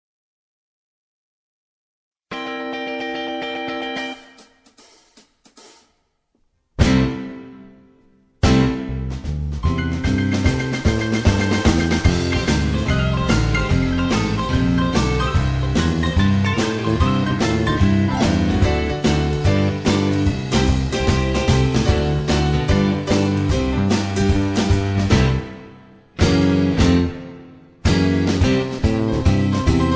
karaoke, mp3 backing tracks
rock and roll, r and b, rock, backing tracks